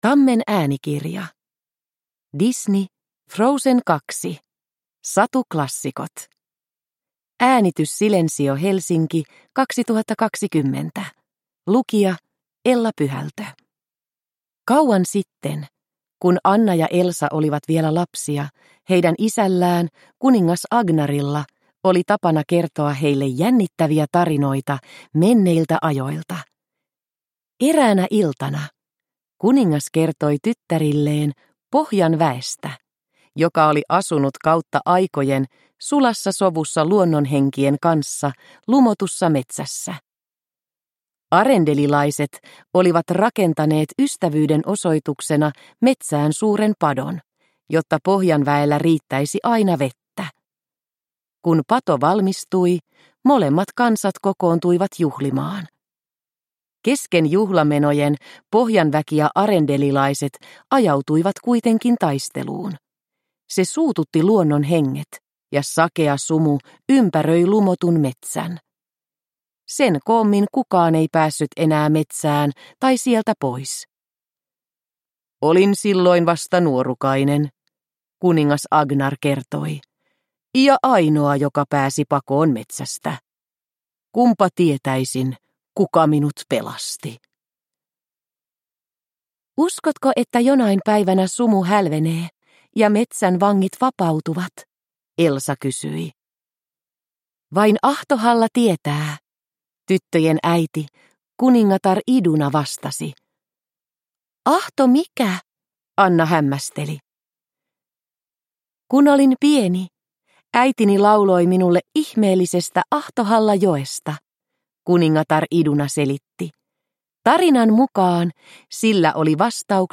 Frozen 2 Satuklassikot – Ljudbok – Laddas ner